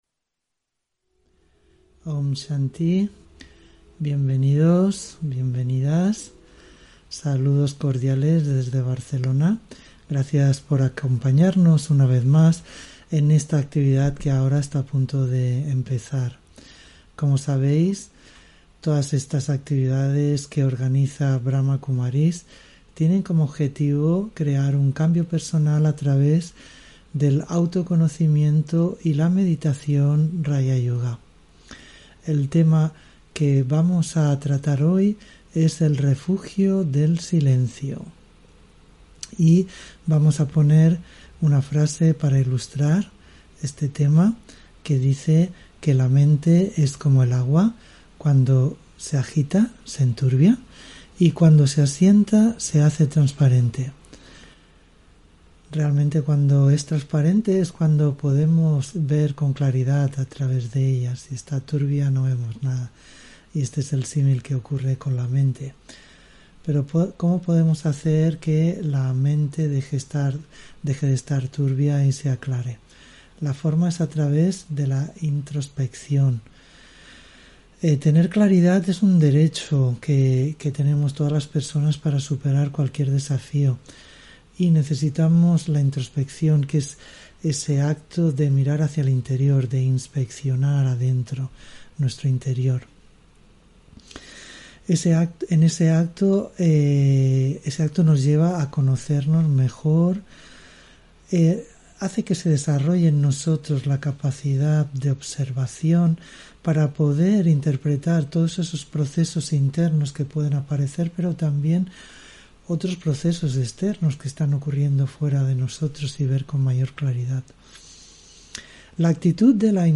Meditación Raja Yoga y charla: El refugio del silencio (15 Julio 2021) On-line desde Barcelona